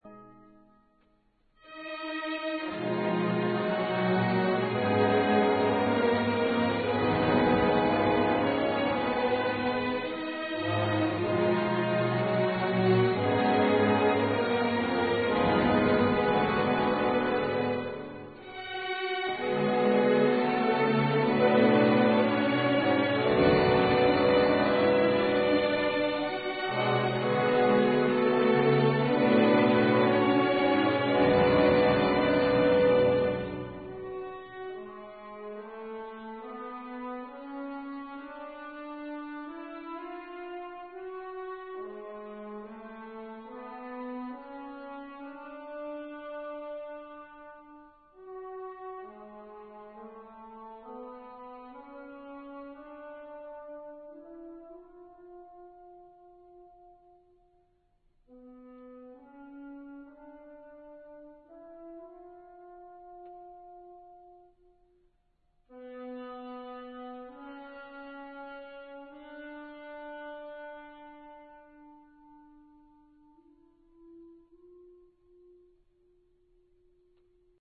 بخشهایی از موومان اول نیز در موومان چهارم تکرار می گردد.